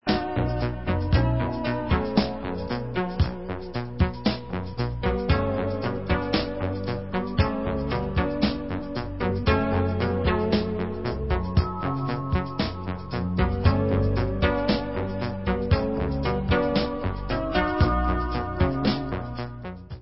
Pop/Symphonic